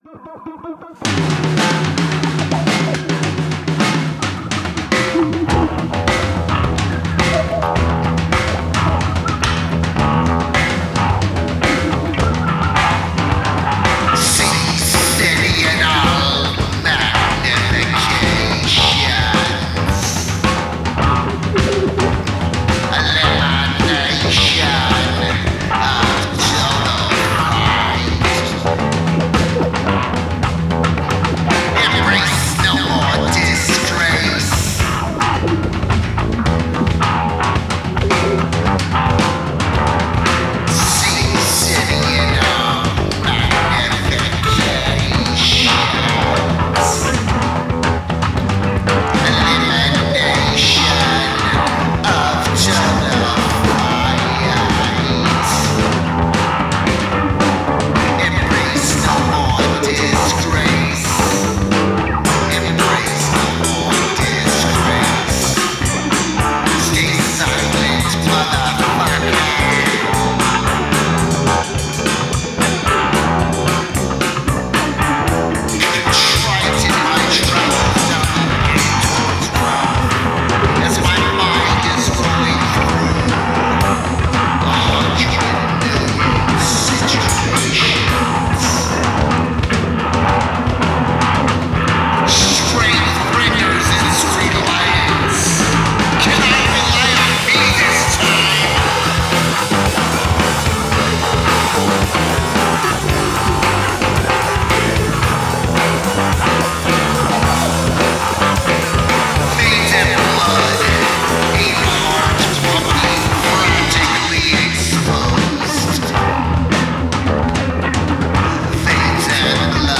Percussion
Guitar
Bass